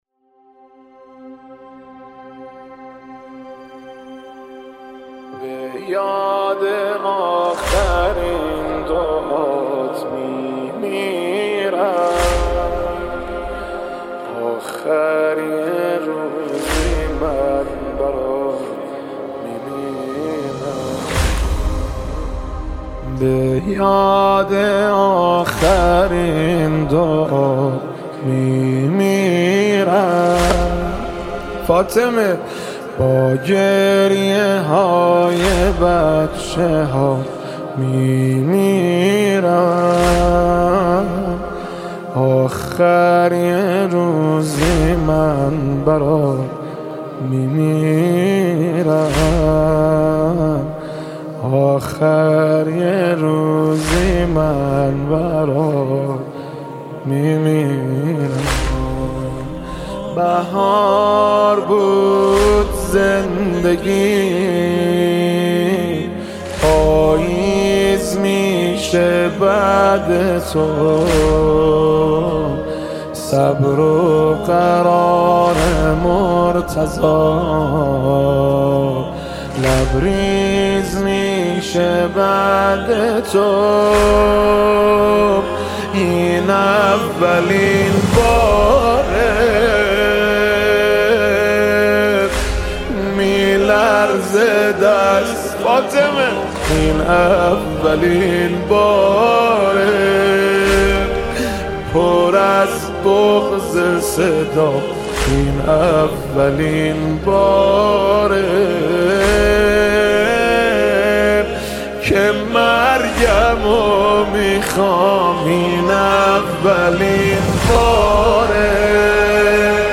مداحی مذهبی